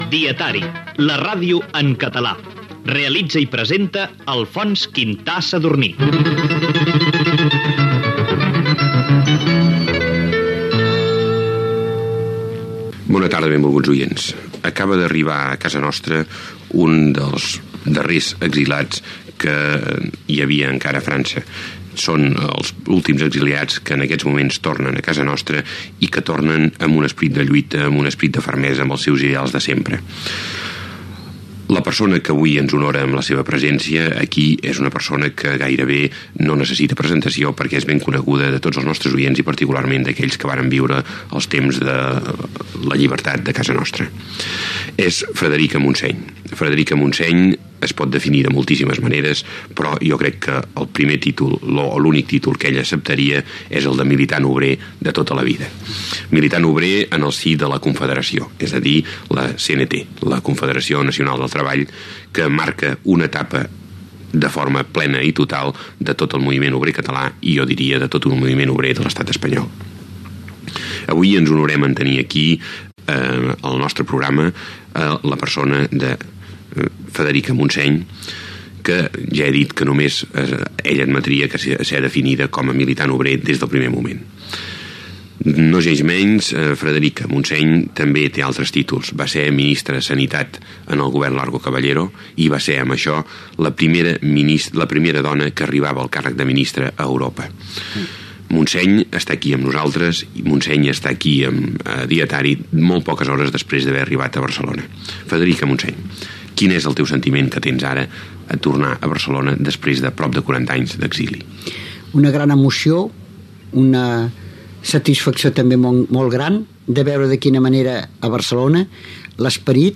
Careta del programa (amb veu de Josep Cuní), presentació i entrevista a la política i sindicalista Federica Montseny pocs dies després de retornar de l'exili.
Informatiu